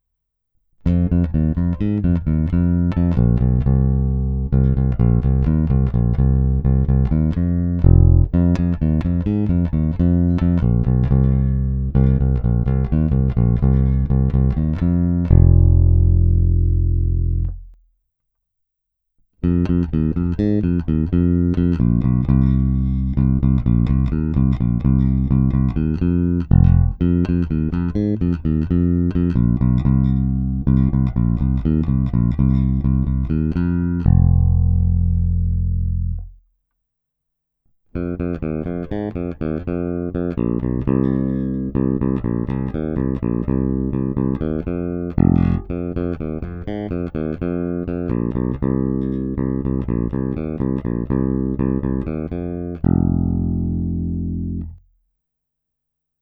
Jestliže zvukový projev lípy je obecně měkčí, temnější, jelikož se jedná o měkké dřevo, snímače tento projev upozadily, zvuk je naprosto klasický průrazně jazzbassový, s pořádnou porcí kousavých středů.
Není-li uvedeno jinak, následující nahrávky jsou provedeny rovnou do zvukové karty, jen normalizovány, jinak ponechány bez úprav.